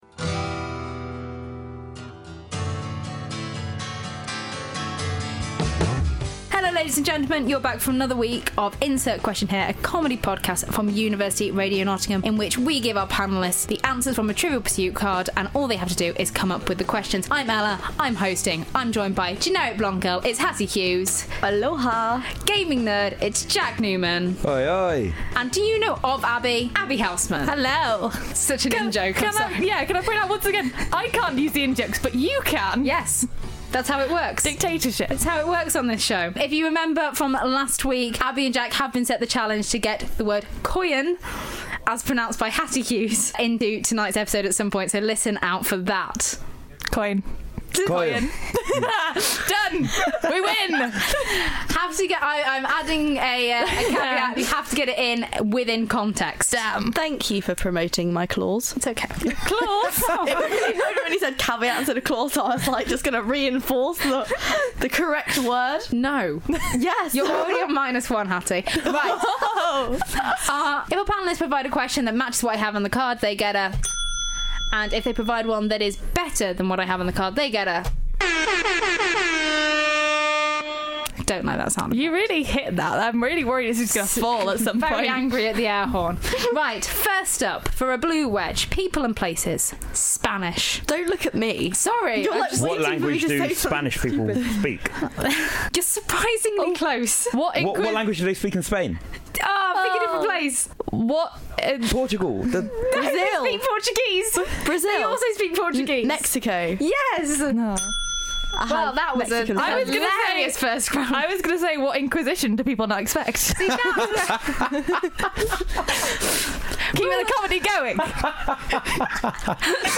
4 students. 1 pack of children's Trivial Pursuit cards.